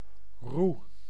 Ääntäminen
Synonyymit stem cuts rod reed whipping staff switch stalk walking stick sugar cane white cane blind man's cane molasses cane the cane six of the best stick pole Ääntäminen US : IPA : [keɪn] Tuntematon aksentti: IPA : /kʰeɪn/